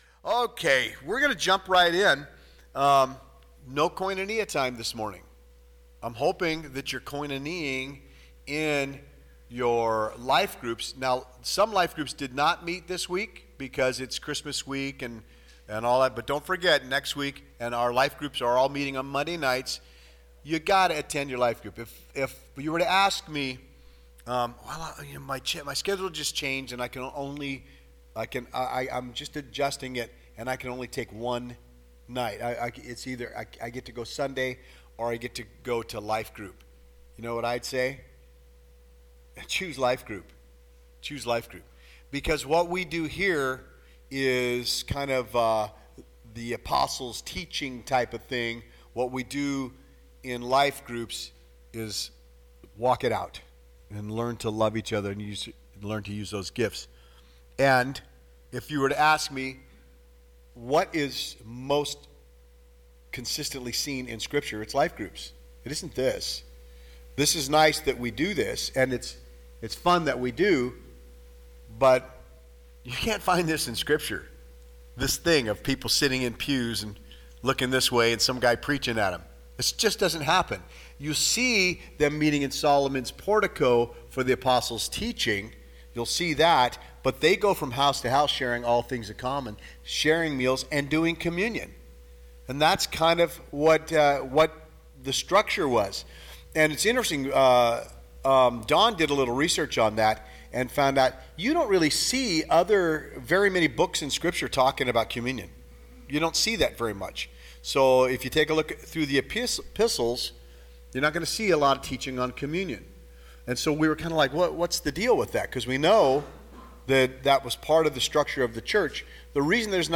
12-8-24 PRE-SERMON - WHY WE DO WHAT WE DO AT THE WAY | The Way Church Sutherlin